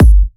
VFH1 128BPM Southern Kick.wav